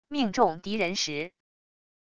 命中敌人时wav音频